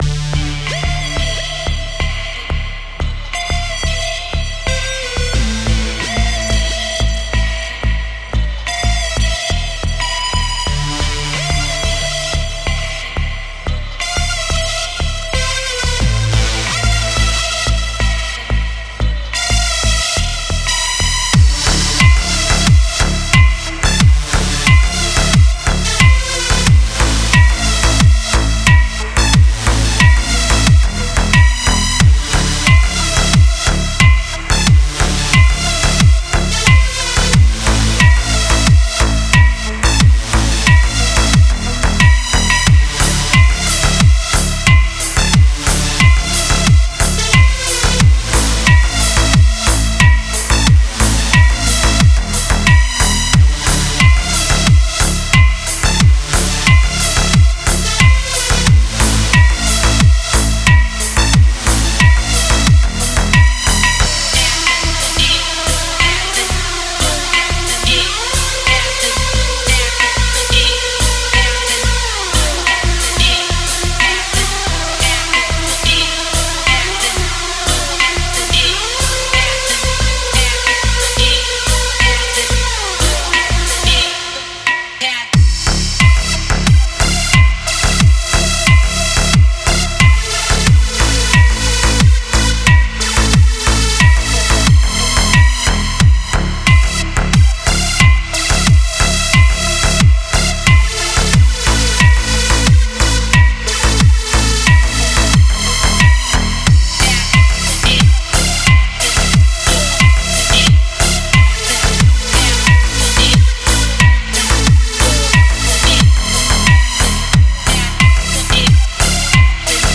Genre: Witch House.